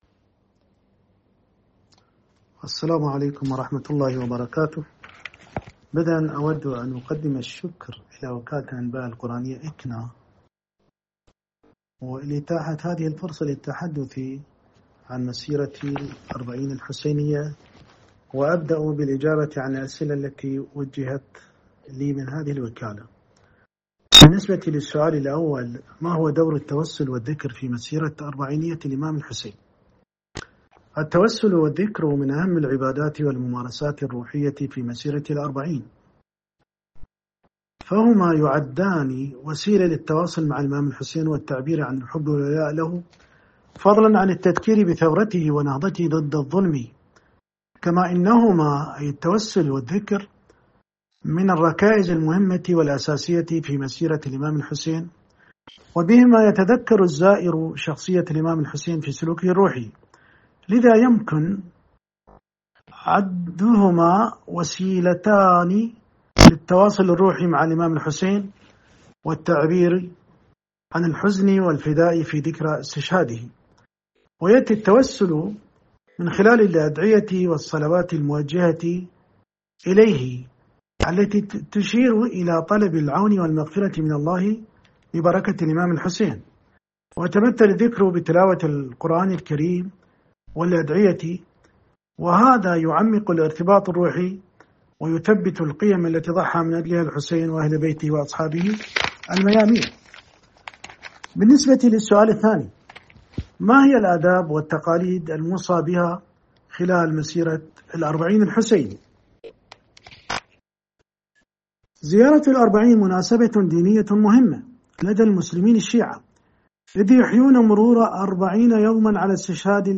أكاديمي عراقي في حوار مع "إکنا" يشرح؛